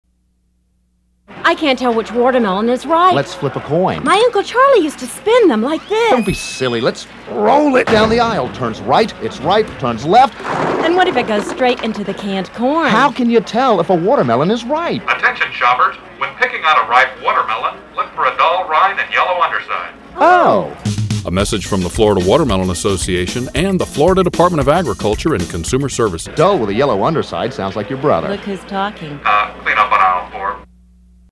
Florida_Watermelon_Radio_Spot_2.mp3